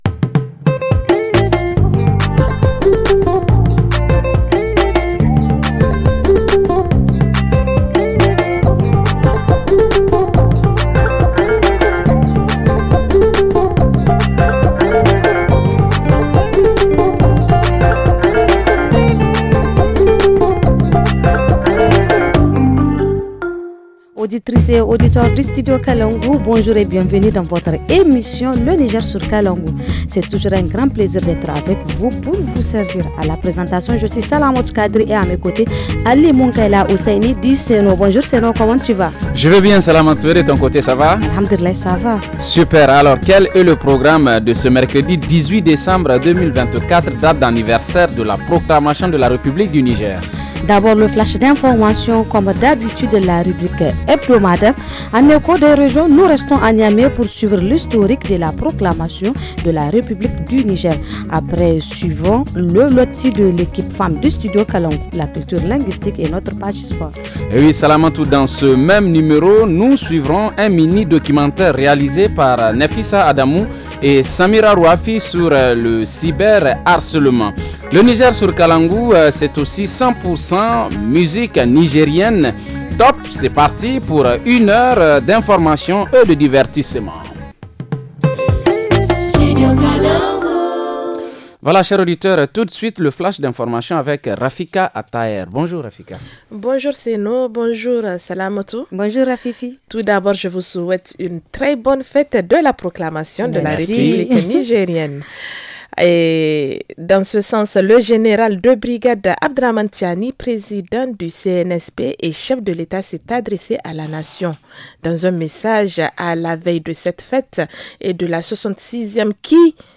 1. Le titre « KAUNAR JUNA » de la chorale universitaire